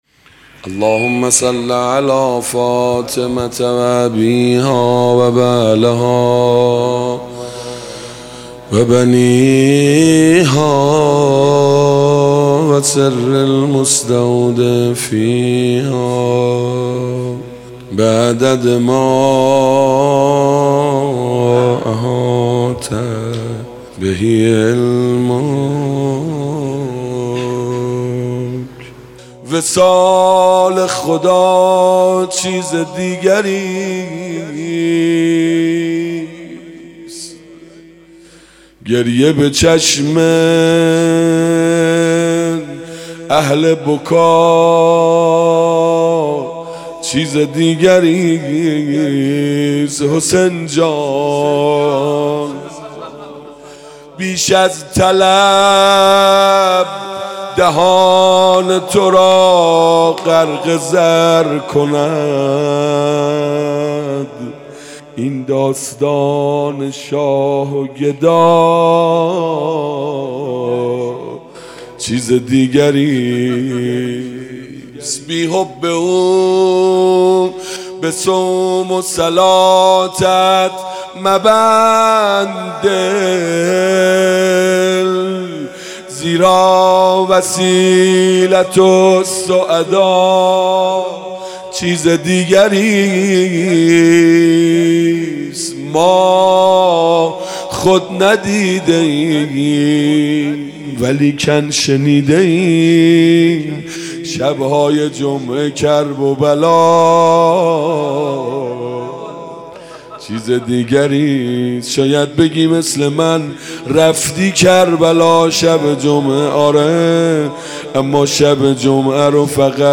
شب عاشورا محرم 97 - مدح